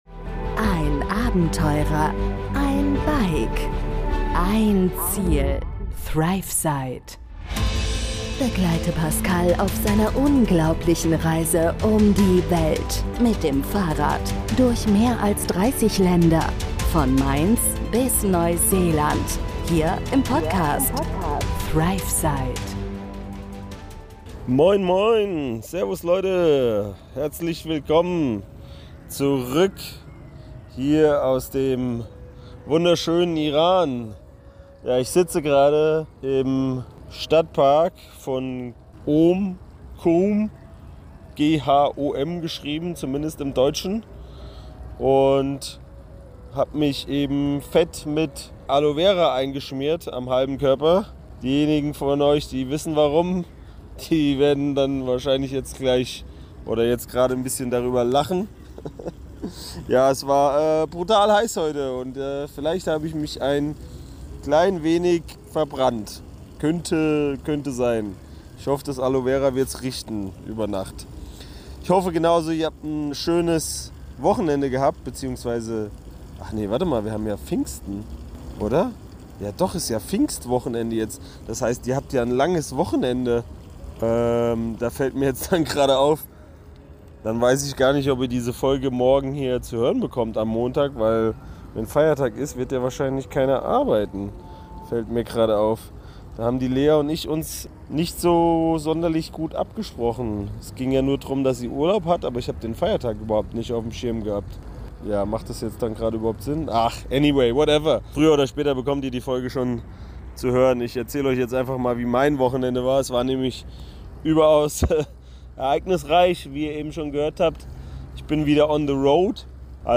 Gerade sitze ich im Stadtpark von Ghom und genieße das Wetter – naja, so gut wie möglich, nachdem ich mich fast verbrannt habe .